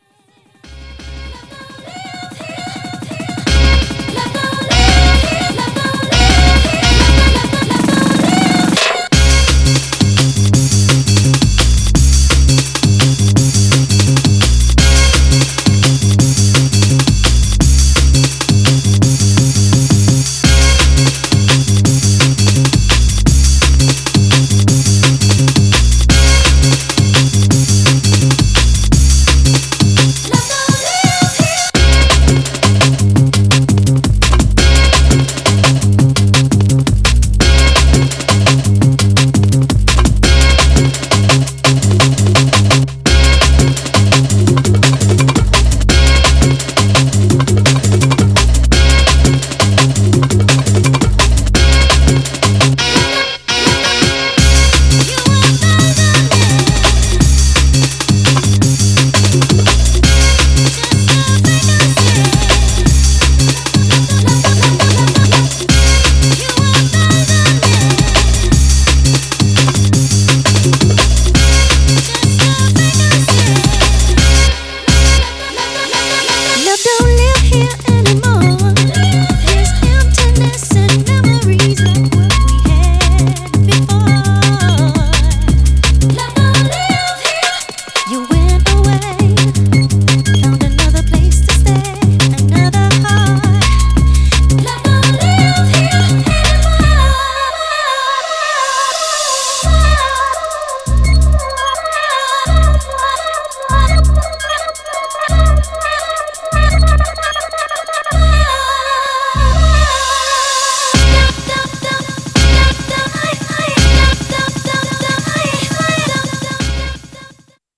Styl: Drum'n'bass, Jungle/Ragga Jungle
* hraje dobře